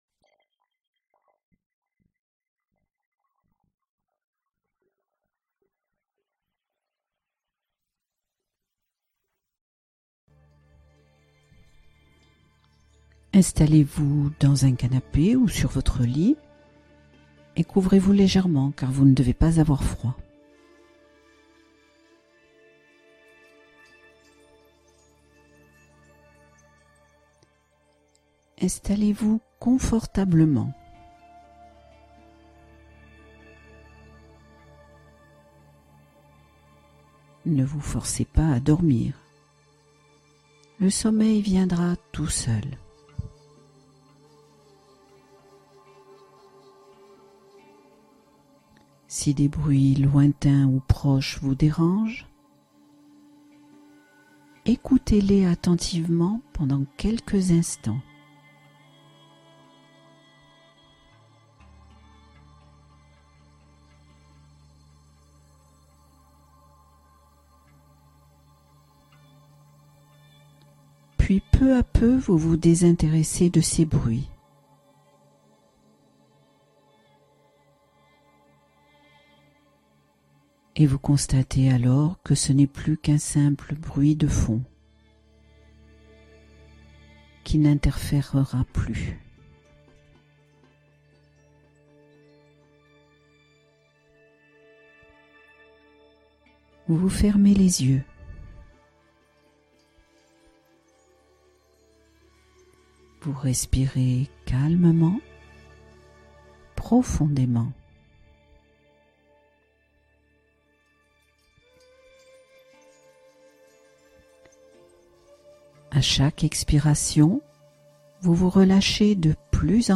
Glissez vers un sommeil réconfortant : la relaxation guidée préférée des anxieux